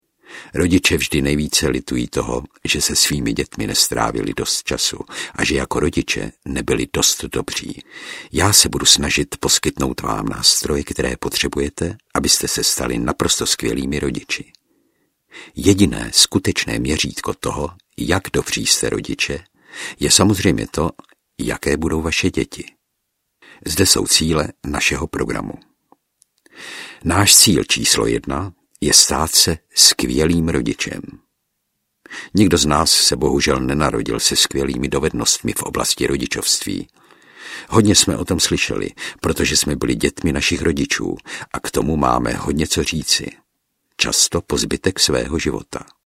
Tajemství výchovy skvělých dětí audiokniha
Ukázka z knihy